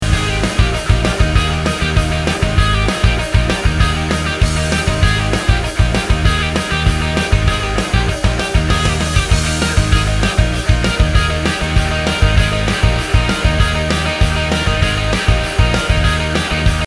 1 – Full Version With Guide Drum Track
2 – Drumless Version With Click